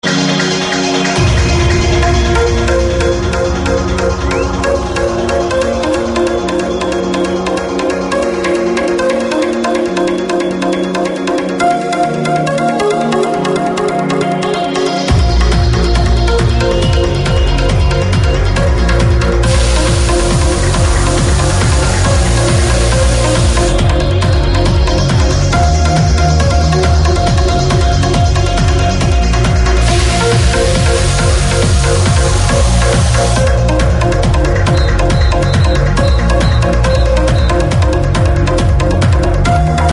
Powered by: Trance Music & vBulletin Forums